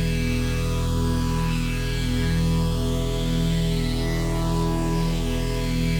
Index of /musicradar/dystopian-drone-samples/Non Tempo Loops
DD_LoopDrone4-E.wav